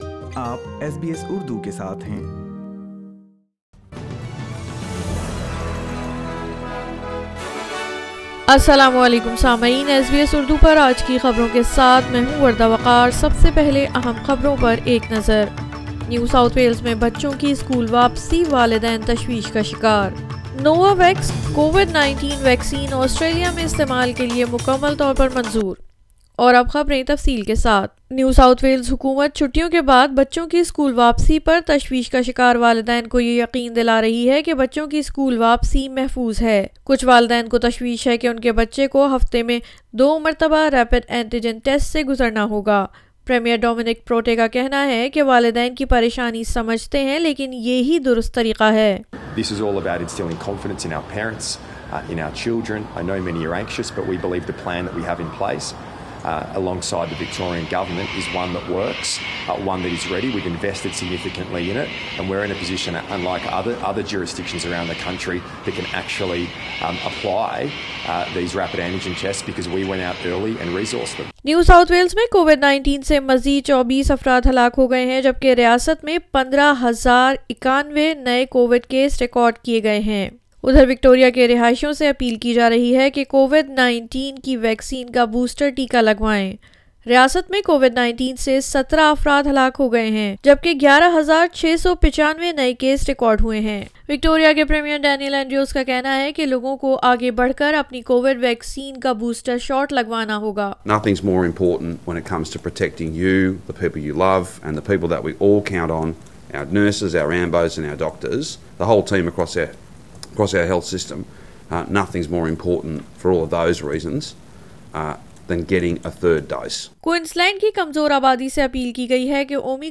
SBS Urdu News 24 January 2022